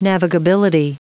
Prononciation du mot navigability en anglais (fichier audio)